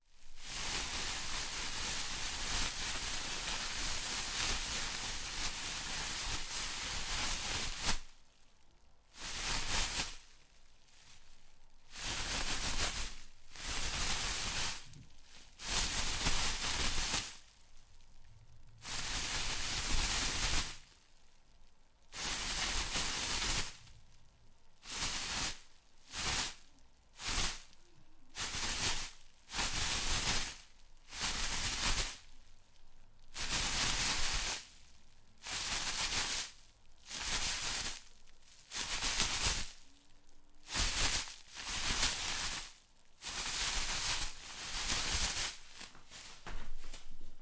衣服沙沙作响
描述：实时记录起毛和摩擦一块布。
Tag: 沙沙 现场记录